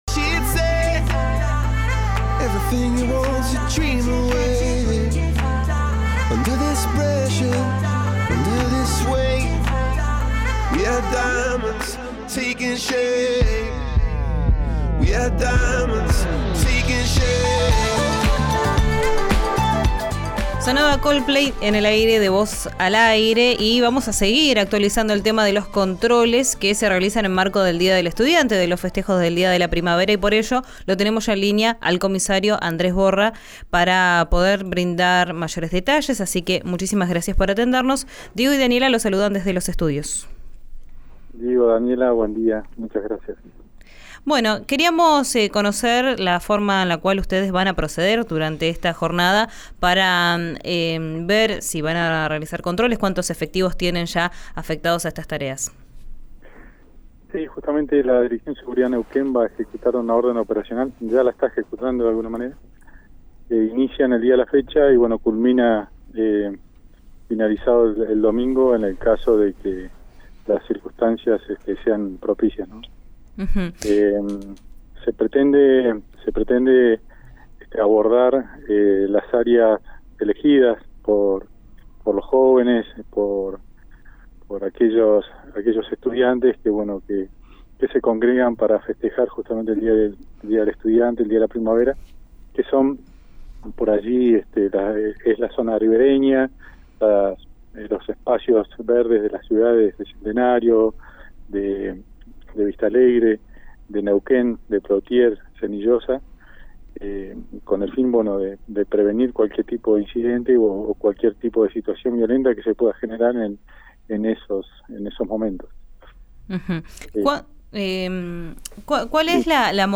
En diálogo con “Vos al Aire” en RIO NEGRO RADIO, expuso que también habrá presencia policial sobre los espacios verdes de Vista Alegre, Centenario, Senillosa, Plottier y Neuquén.